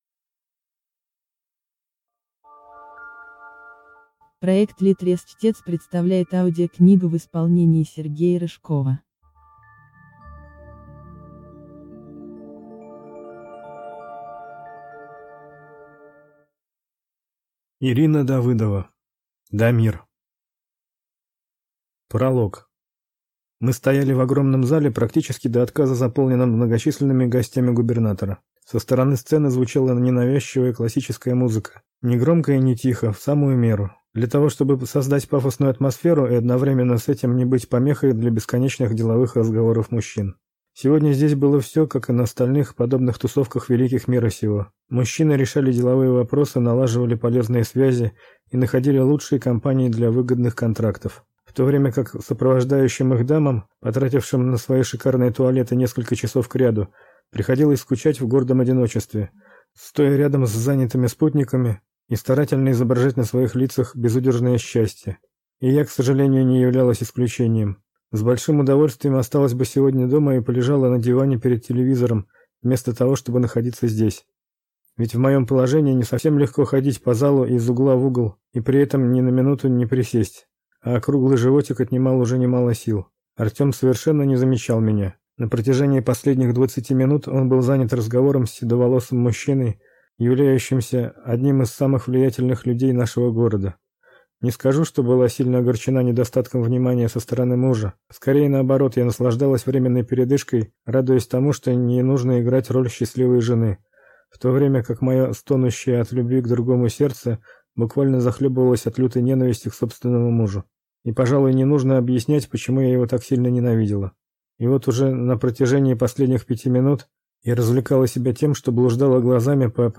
Аудиокнига Дамир | Библиотека аудиокниг